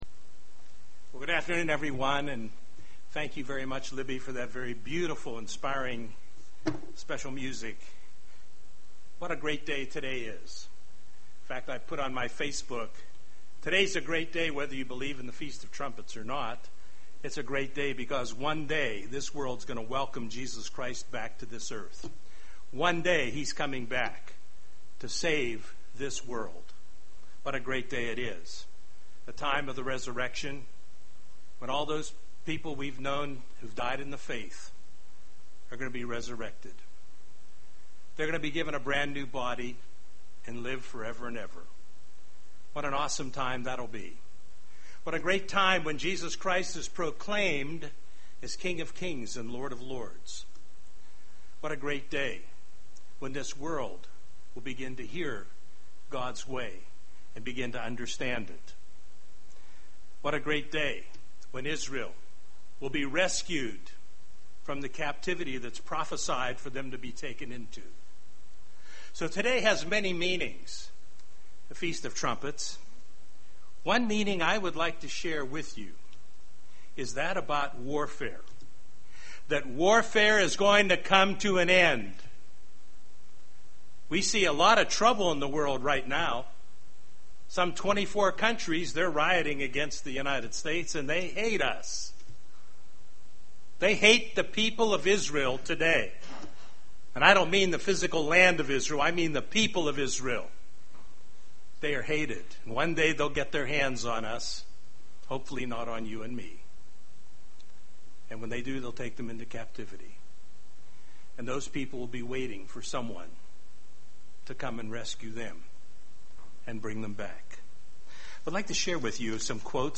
There will be a war to end all war and Jesus Christ is going to be the ultimate victor UCG Sermon Transcript This transcript was generated by AI and may contain errors.